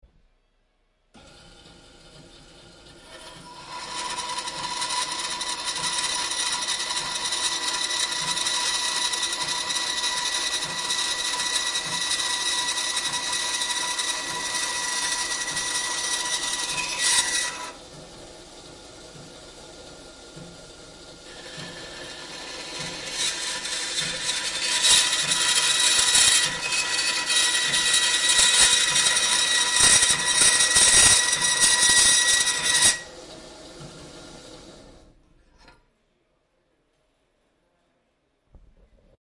金属锯
描述：我录制了这个金属切割轮锯的声音。它正在切割一些金属。
标签： 切割 金属 恐怖 电动 切割 吓人
声道立体声